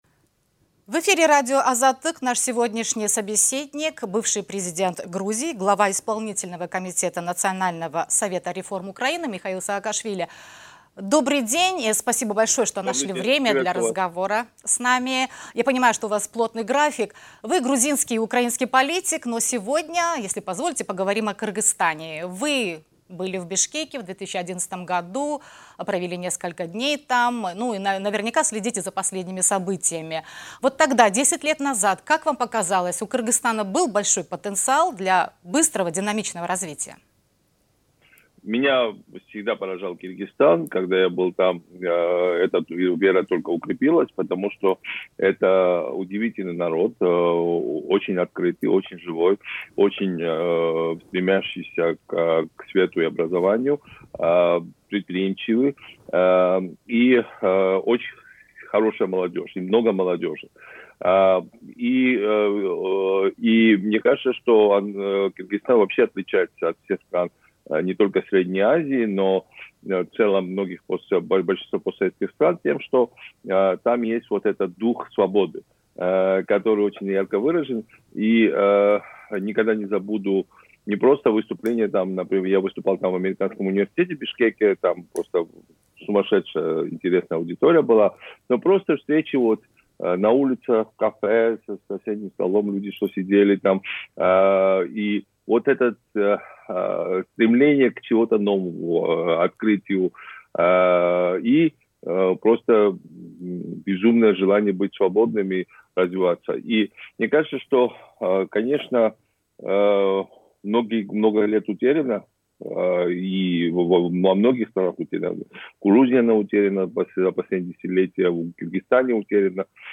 Бывший президент Грузии, глава Исполнительного комитета Национального совета реформ Украины Михаил Саакашвили дал эксклюзивное интервью радио “Азаттык”. Политик рассказал, почему реформы и борьба с коррупцией в Грузии, в отличие от Кыргызстана, были успешны.